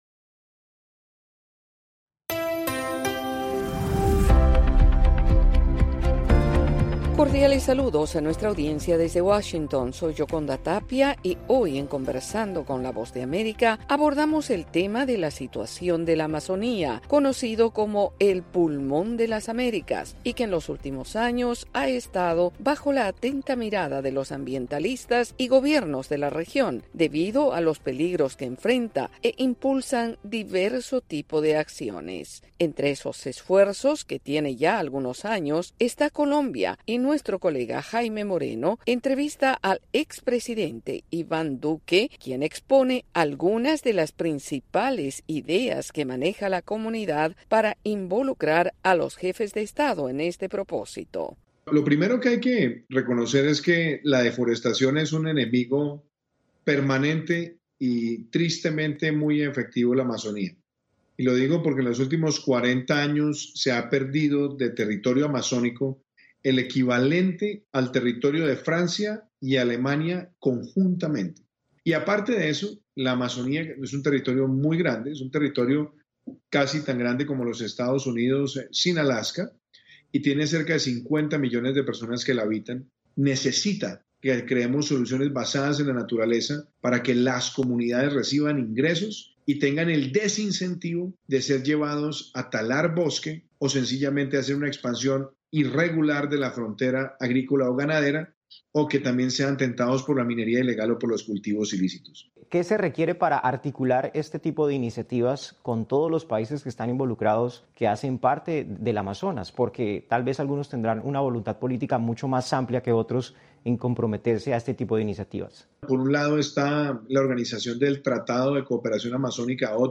Conversamos con el expresidente colombiano Iván Duque exponiendo diversos pasos que pueden dar los Estados de la región para preservar la Amazonía.